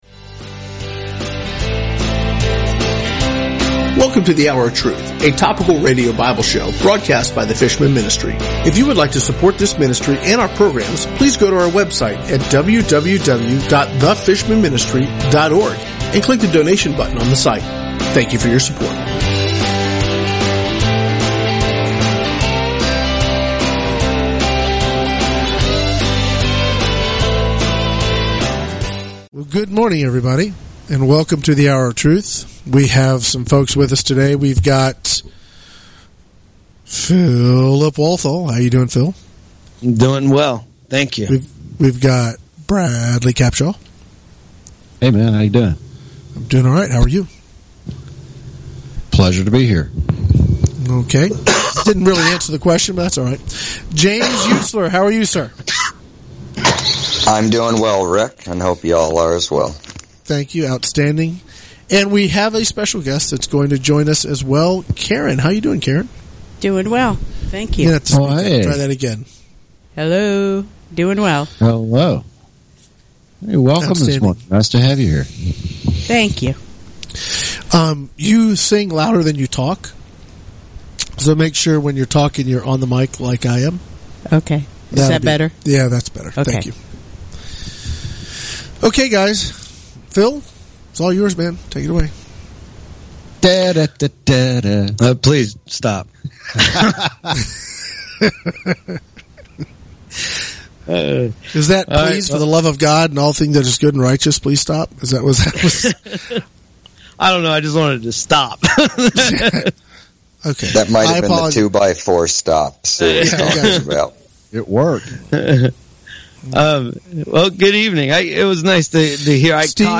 Sunday Hour of Truth Service 03/20/2016 | The Fishermen Ministry